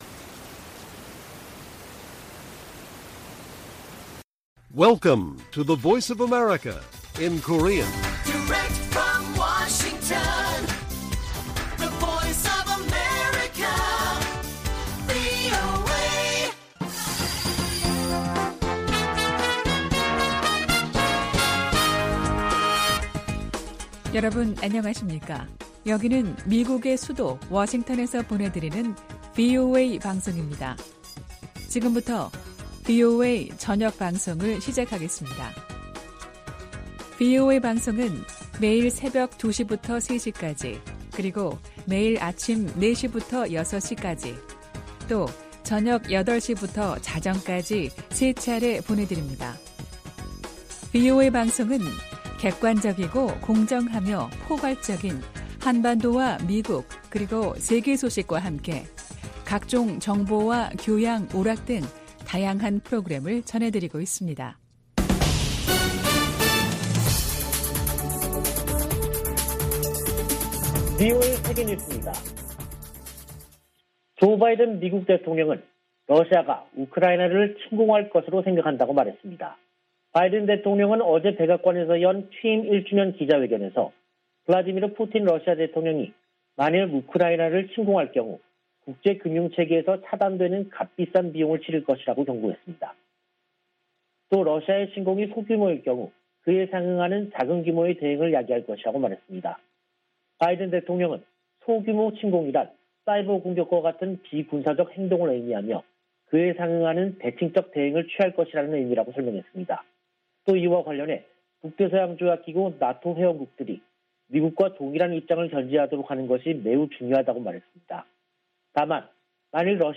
VOA 한국어 간판 뉴스 프로그램 '뉴스 투데이', 2022년 1월 20일 1부 방송입니다. 북한이 핵과 ICBM 시험 등의 유예를 철회할 것을 시사하며 대미 압박을 강화하고 있습니다. 미 공화당 중진 상원의원이 북한의 미사일 시험에 대해 핵전쟁 승리를 위한 전술무기 개발 목적이라고 경고했습니다. 유럽연합(EU)이 유엔 안보리 긴급 회의 개최와 관련해 북한의 대량살상무기(WMD) 확산 방지를 위해 노력할 것이라는 점을 재확인했습니다.